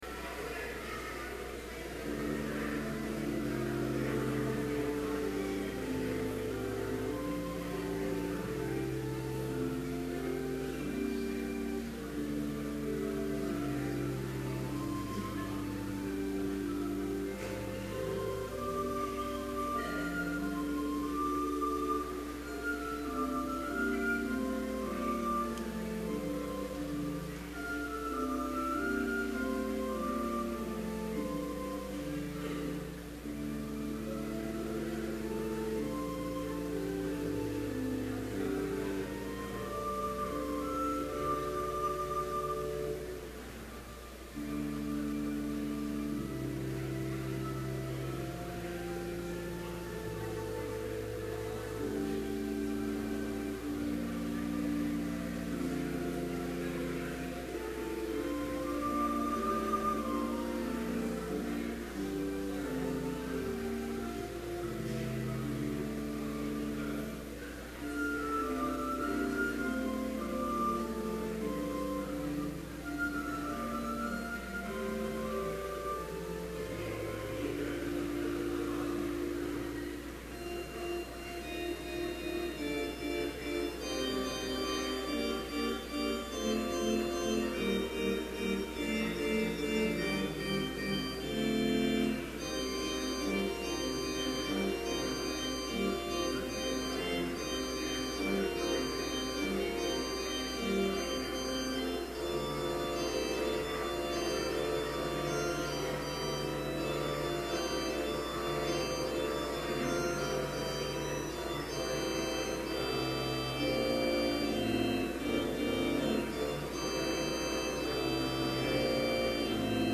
Complete service audio for Chapel - December 8, 2011